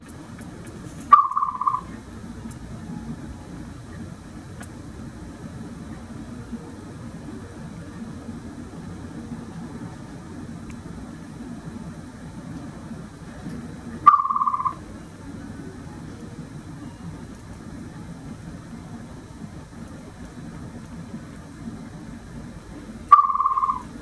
サスキアの鳴き声
● サスキアは 普段 まったく しゃべらないので、リスには声帯が無い、と言う人もいましたが、実は 声帯を持っていて、サスキアは たまに、「 キュルルルルルル、 キュルルルルルル 」と、声高く鳴きます。
ハンモックや コーナー･ステージで まったりしている（ように見える）時に 鳴きます。
crying.mp3